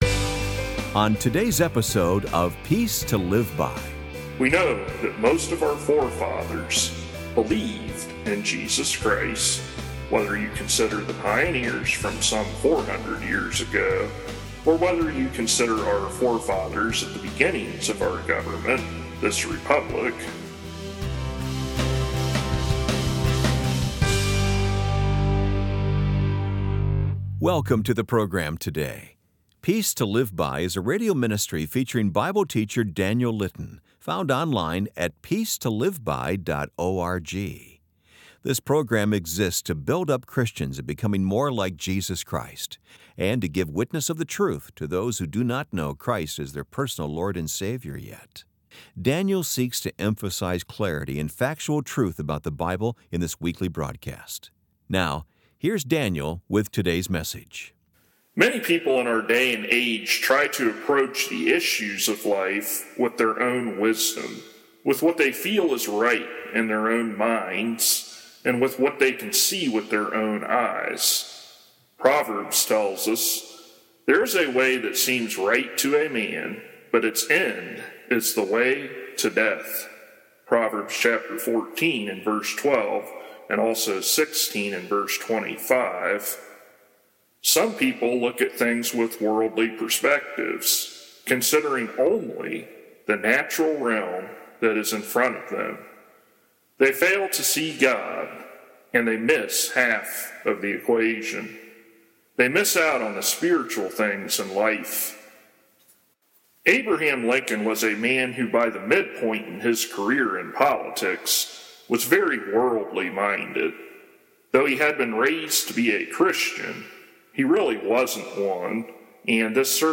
For full sermons without edits for time, tap here to go to downloads page.